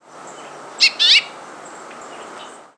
House Finch diurnal flight calls
Perched bird.